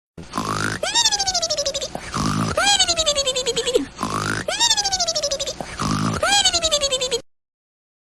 Funny Cartoon Snore Sound Effect Free Download
Funny Cartoon Snore